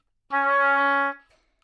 双簧管单音（弹得不好） " 双簧管 D4 攻击力差的舌头
描述：在巴塞罗那Universitat Pompeu Fabra音乐技术集团的goodsounds.org项目的背景下录制。
Tag: 好声音 D4 单注 多重采样 纽曼-U87 双簧管